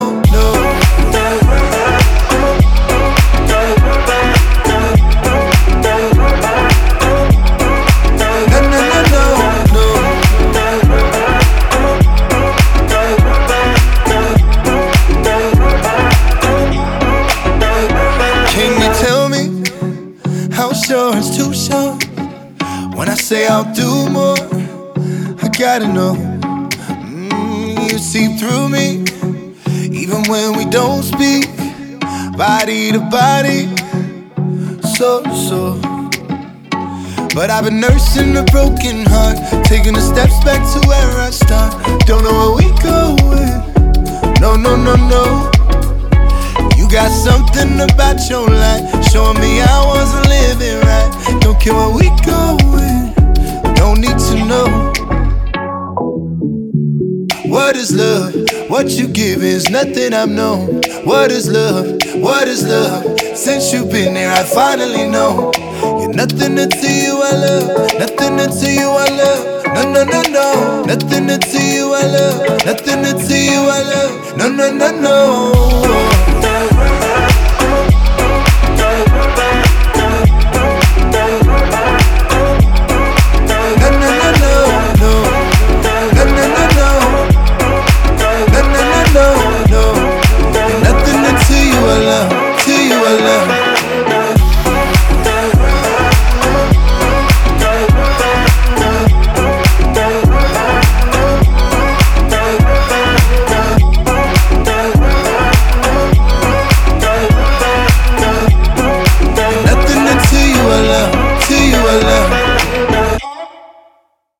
• Качество: 320, Stereo
dance
спокойные
RnB